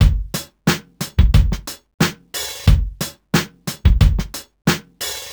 Index of /musicradar/sampled-funk-soul-samples/90bpm/Beats
SSF_DrumsProc1_90-01.wav